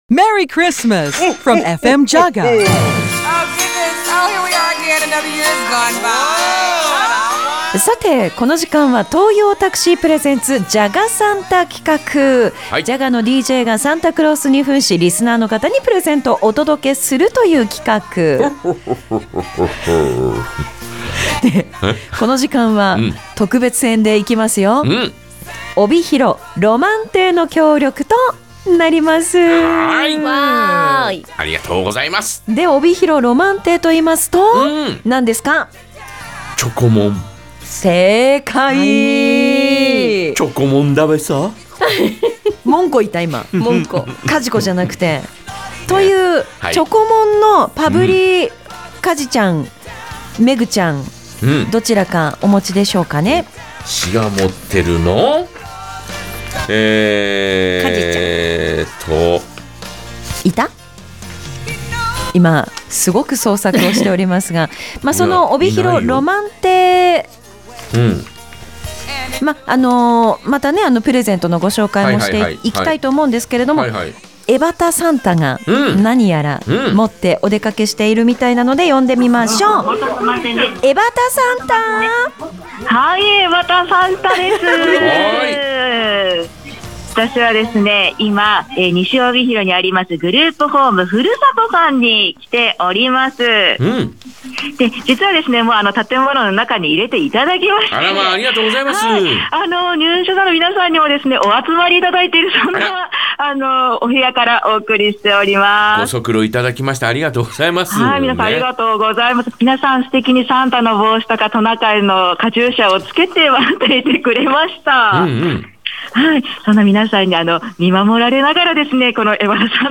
12月24日、グループホームふるさとにFMJAGAからサンタさんがやってきました。